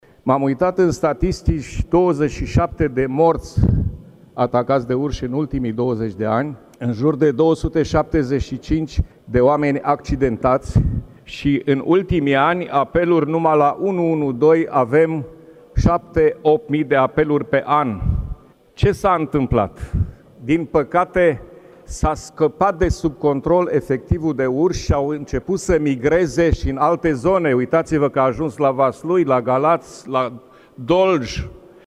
Senatorul PNL, Gheorghe Flutur: „S-a scăpat de sub control efectivul de urși și au început să migreze și în alte zone”